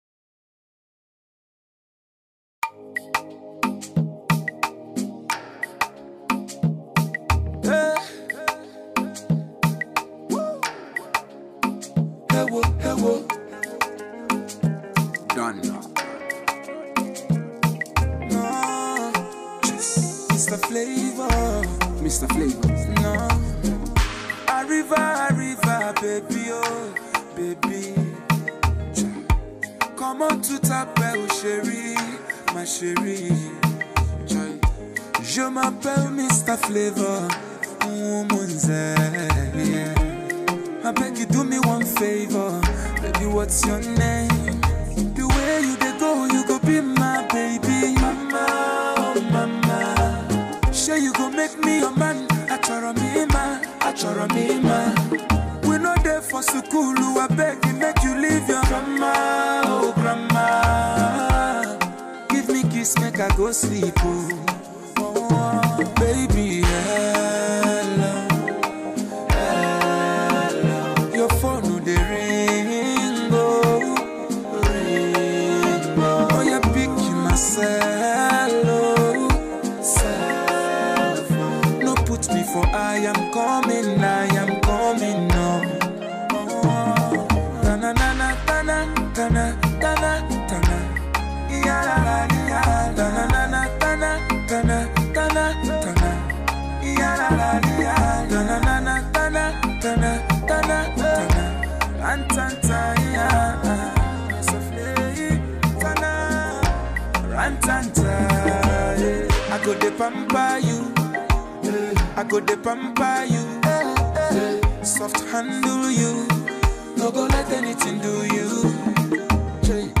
highlife
melodious song